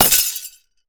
ice_spell_impact_shatter_07.wav